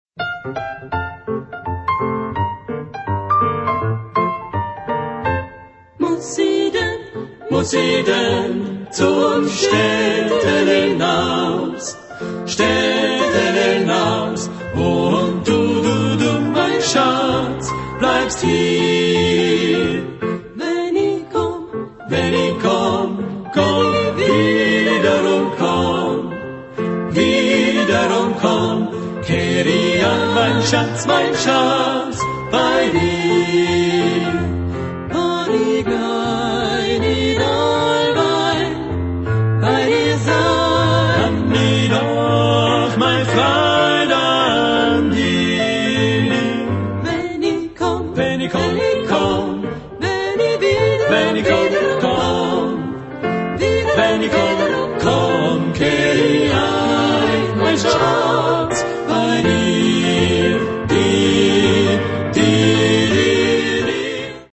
Genre-Style-Forme : Profane ; Variété ; Pop
Caractère de la pièce : énergique ; joyeux
Type de choeur : SATB  (4 voix mixtes )
Instruments : Piano (1)
Tonalité : fa majeur